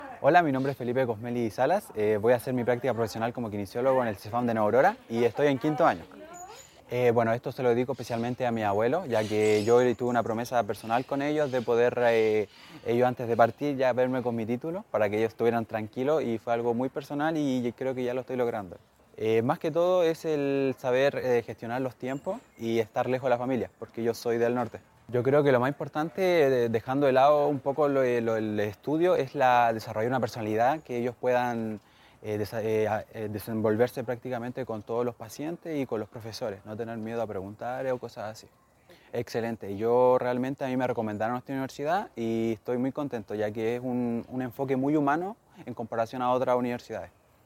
Compartimos con ustedes parte de lo que conversamos con algunos estudiantes, quienes desde su historia personal, evaluaron lo que significa para ellos, el inicio de sus prácticas profesionales, a fines de este mes.
Testimonios